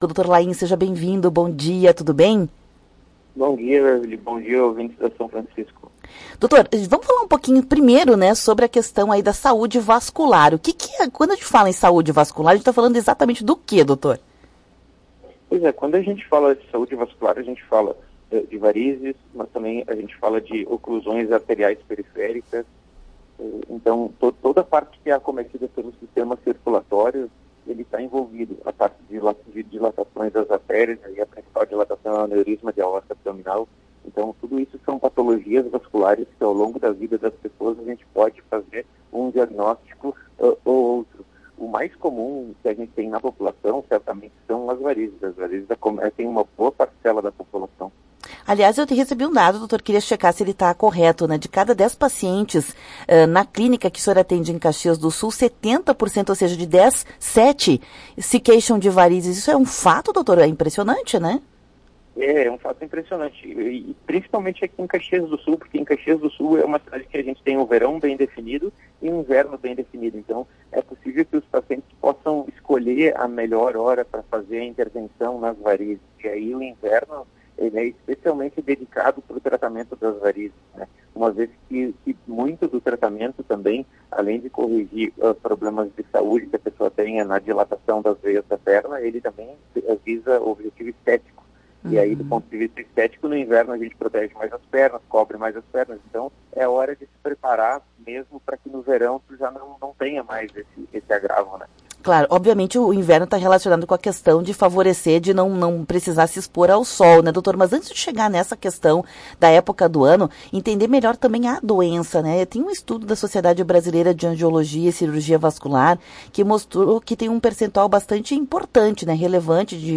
O médico especialista concedeu entrevista sobre o assunto ao programa Temática.